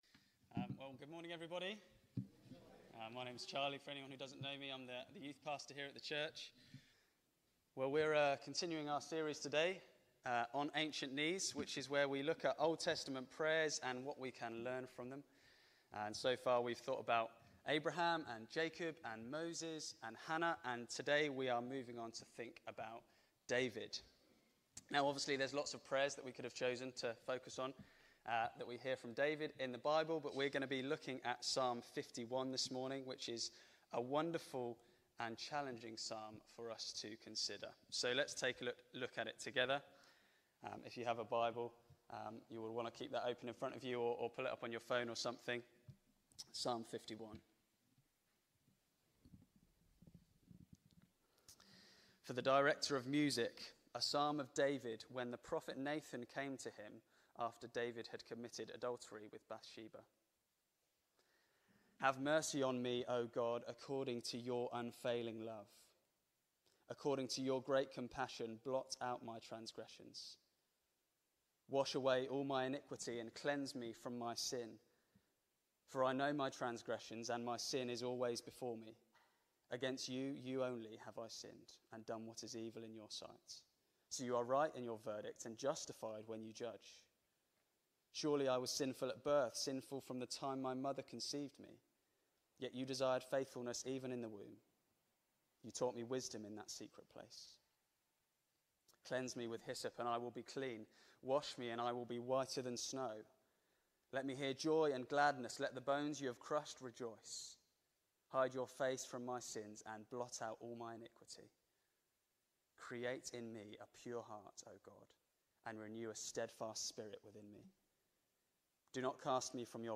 Sermon-4th-February-2024.mp3